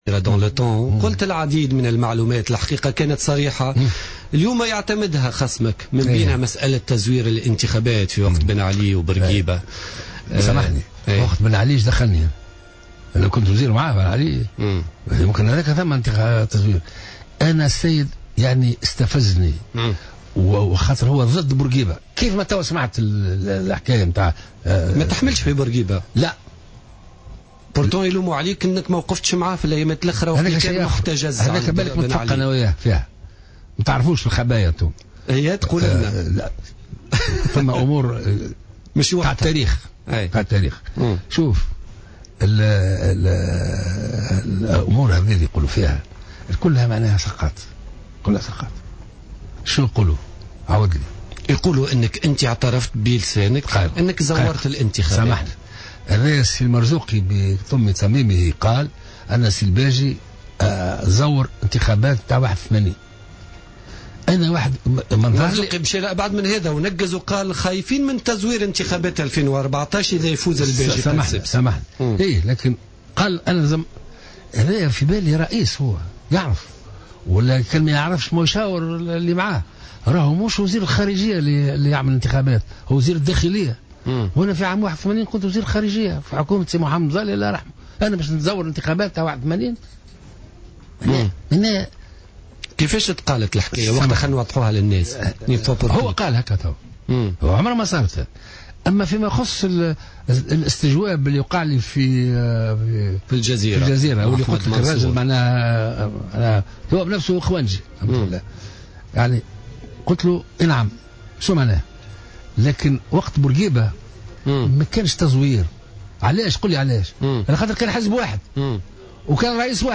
Sur le plateau de Jawhara Fm, le candidat finaliste au second tour de l'élection présidentielle, Béji Caïd Essebsi a répondu jeudi aux accusations du camp adverse sur les craintes de falsification du scrutin.